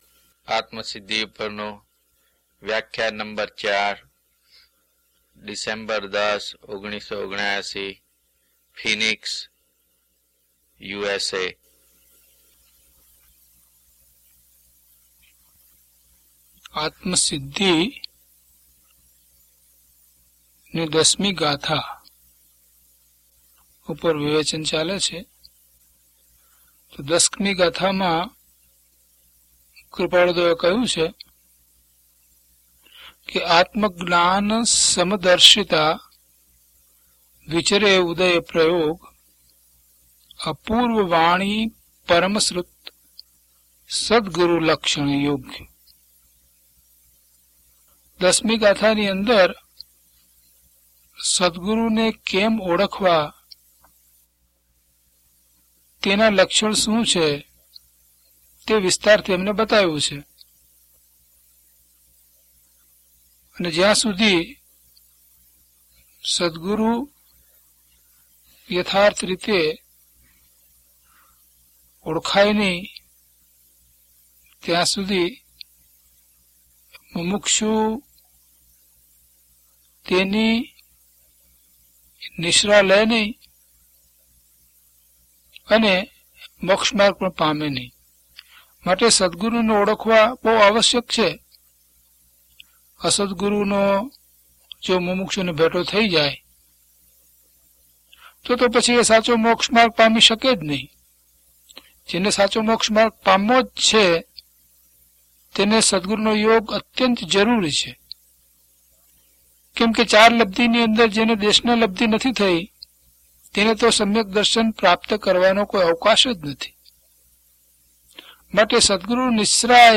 DHP013 Atmasiddhi Vivechan 4 - Pravachan.mp3